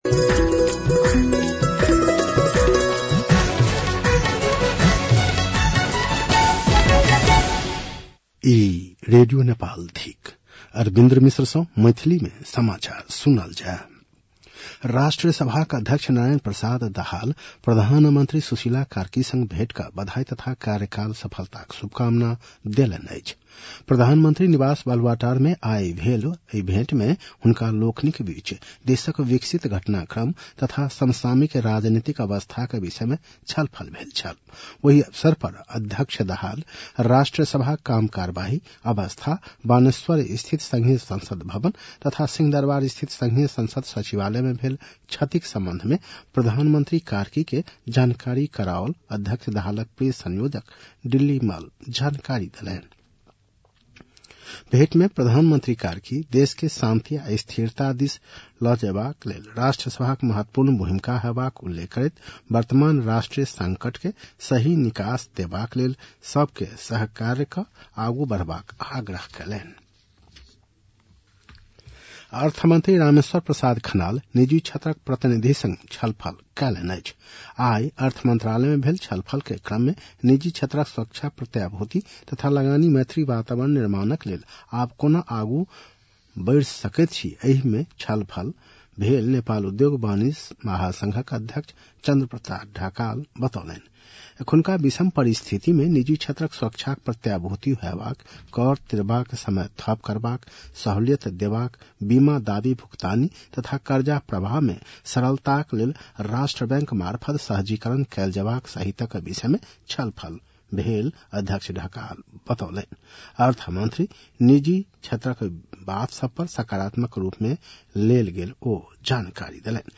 मैथिली भाषामा समाचार : ३१ भदौ , २०८२